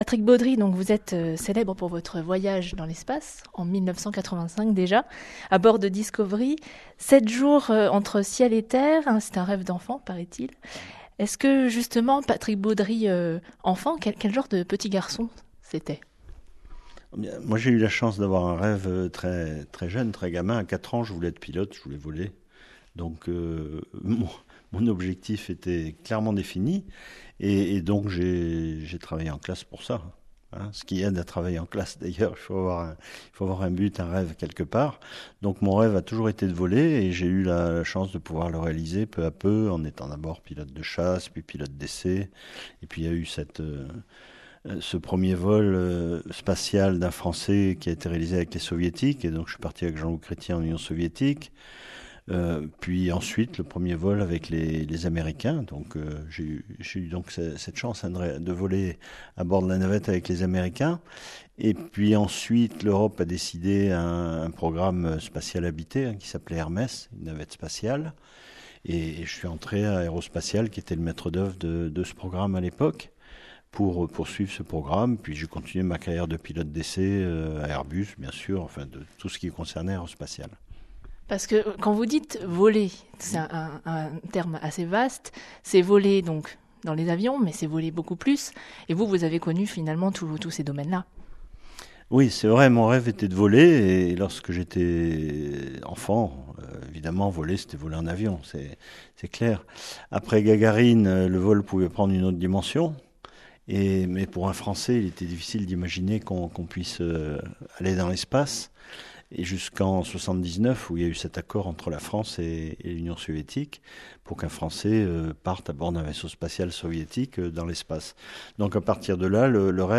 Patrick Baudry interviewé sur la radio FranceBleu